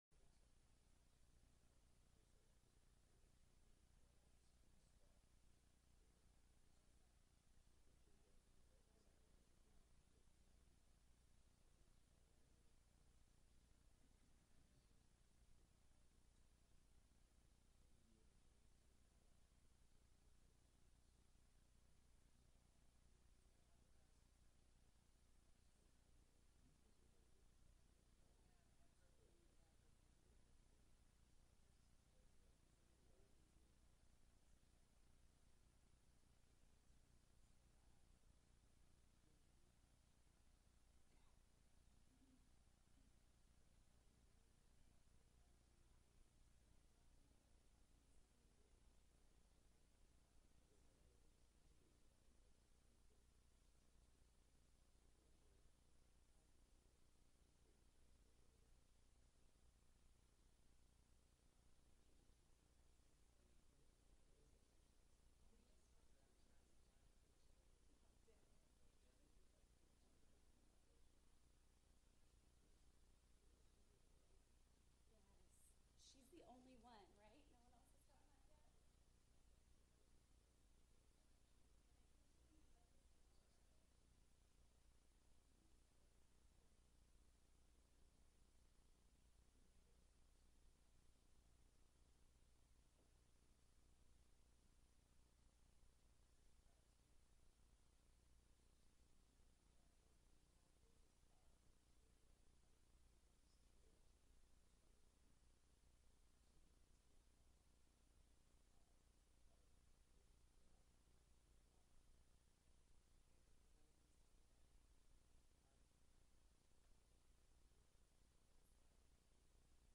Planning and Zoning Commission on 2025-06-04 9:00 AM - Regular Meeting - Jun 04, 2025